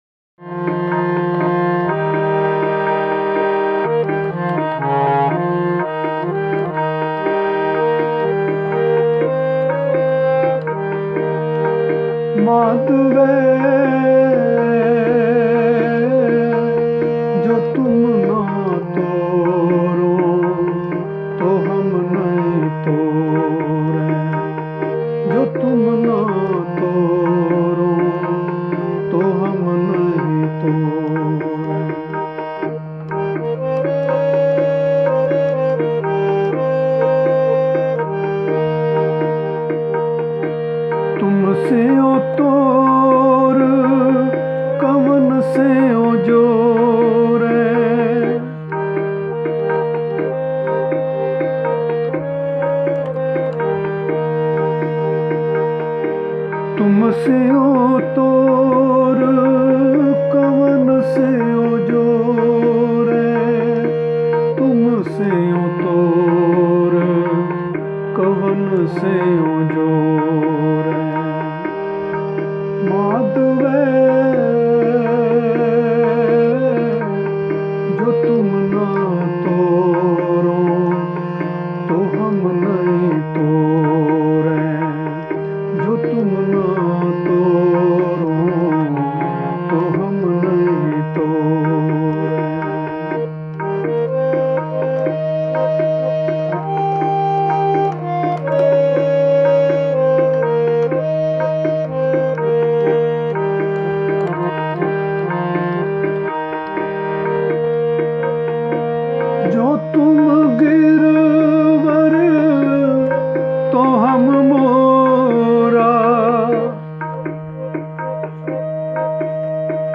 The Website is devoted to the propagation of Sikh religion, Sikh Tenets, Gurbani, Kirtan and Gurshabad